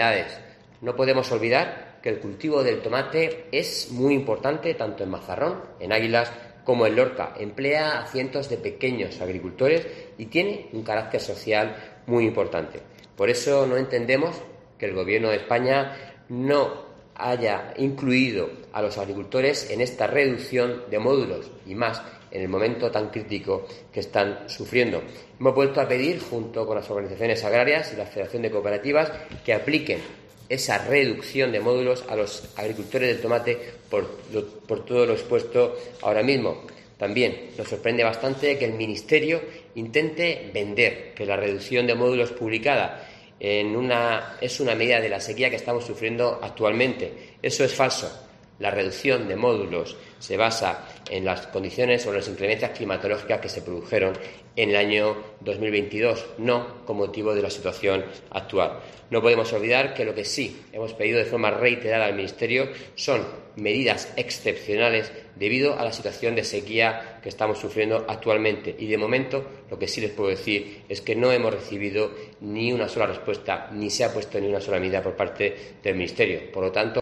Antonio Luengo, consejero de Agua, Agricultura, Ganadería y Pesca